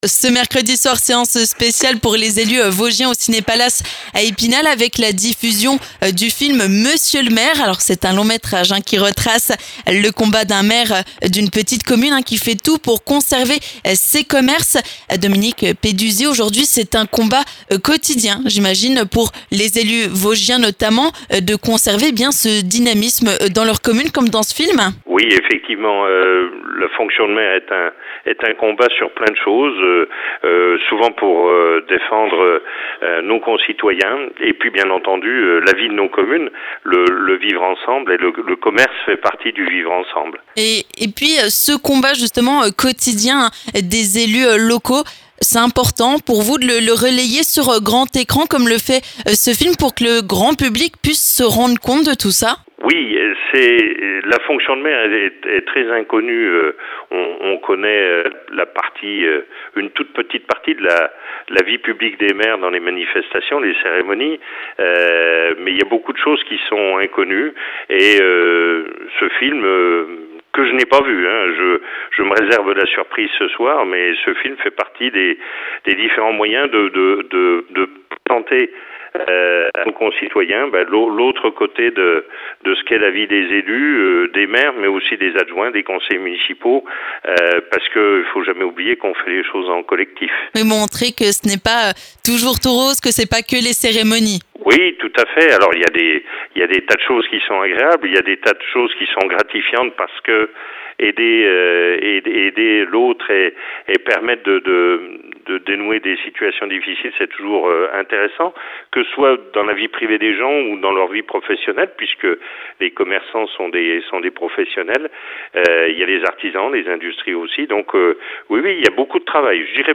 On parle de l'importance de ce film avec le président de l'AMV 88, Dominique Peduzzi.